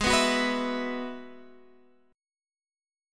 Abm7#5 chord